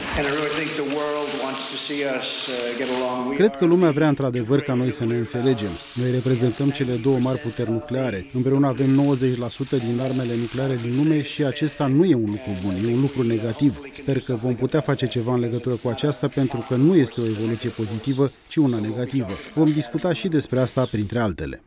Înaintea întrevederii cu uşile închise, Donald Trump şi Vladimir Putin şi-au strâns mâinile şi apoi au făcut scurte declaraţii în faţa presei. Preşedintele Donald Trump a spus că o bună înţelegere cu Rusia este un lucru bun şi că vor fi discutate subiecte variate, de la comerţ la chestiunile militare:
Donald-Trump.wav